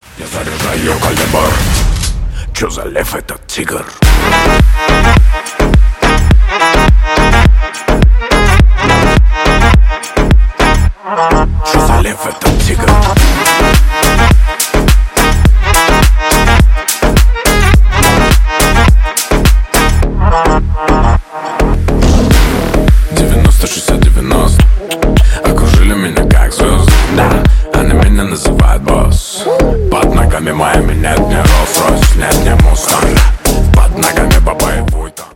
клубные , рэп
мужские